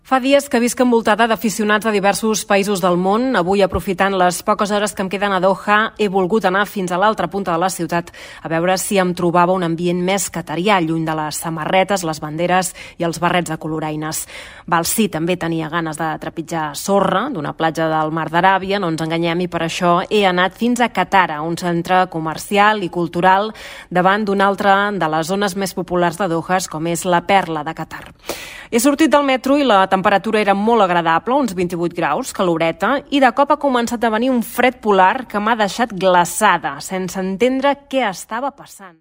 Crònica des de Doha de l'ambient al mundial de futbol masculí de Qatar
Esportiu
FM